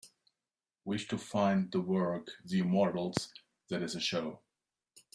Text-to-Speech
Add generated mp3 files